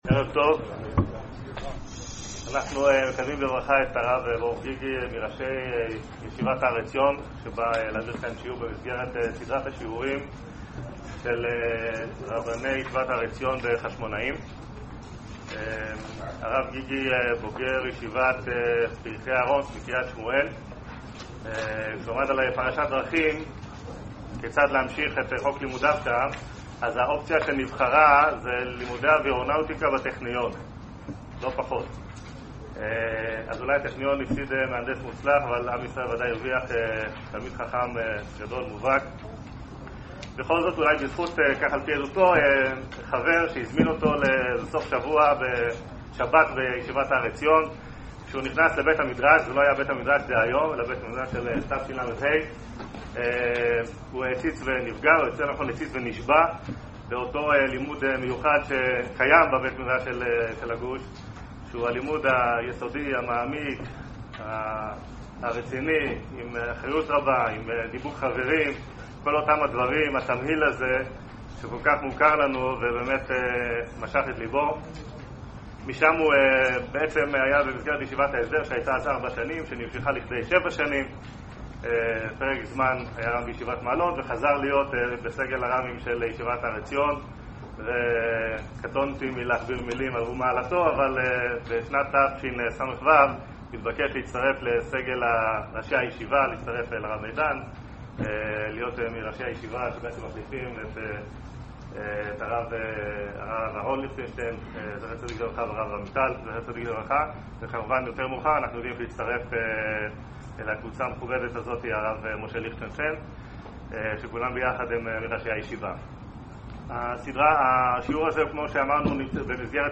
יום ראשון כ' בסיוון תשע"ו בקהילת בית כנסת רימון, חשמונאים